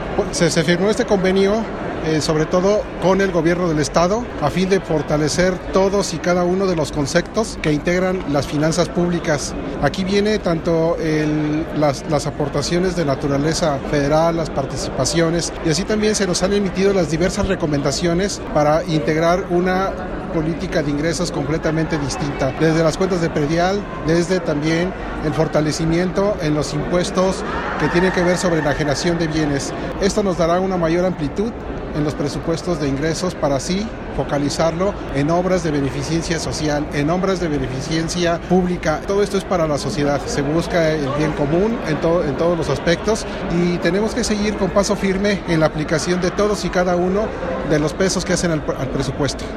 AudioBoletines
La firma del convenio se realizó durante la XLIV Reunión Estatal de Funcionarios Fiscales.
Diego Sinhue Rodríguez – Gobernador del Estado